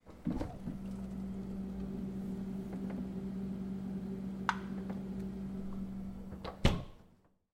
Холодильник: дверка морозильной камеры, открытие/закрытие